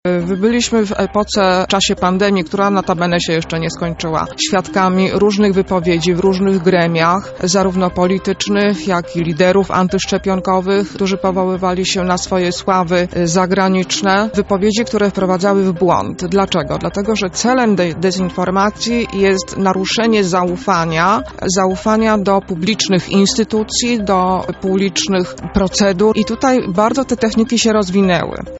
[PORANNA ROZMOWA] Dezinformacja medyczna globalnym problemem XXI wieku?